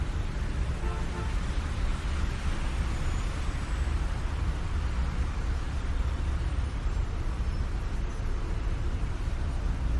Train Passing
Train Passing is a free ambient sound effect available for download in MP3 format.
# train # transit # whoosh About this sound Train Passing is a free ambient sound effect available for download in MP3 format.
316_train_passing.mp3